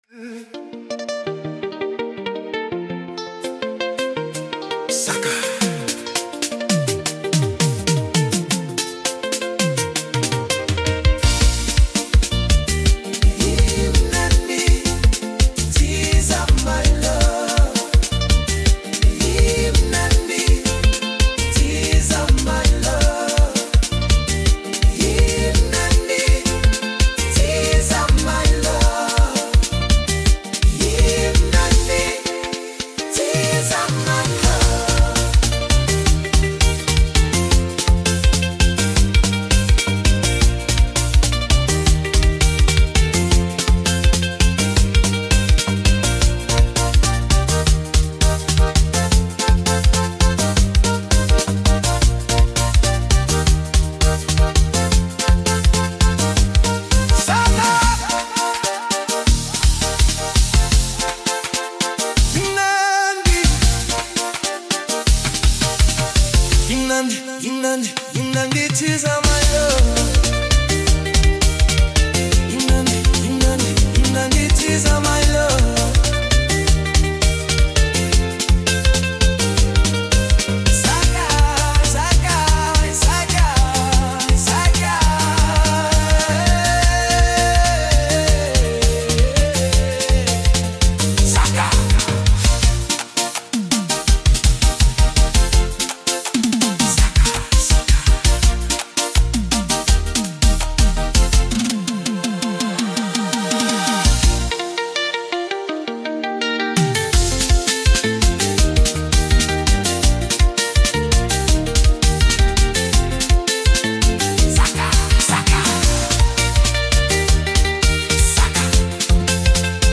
blends rhythm and melody flawlessly